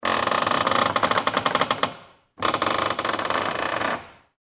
Ambient sound effects
Madera_Audio_madera.mp3